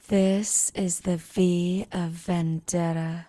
speech-female_hpsModel
Category 🗣 Voices
deterministic female harmonic model sinusoids sms-tools speech vocal sound effect free sound royalty free Voices